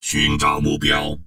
文件 文件历史 文件用途 全域文件用途 Enjo_skill_02_1.ogg （Ogg Vorbis声音文件，长度1.3秒，119 kbps，文件大小：18 KB） 源地址:地下城与勇士游戏语音 文件历史 点击某个日期/时间查看对应时刻的文件。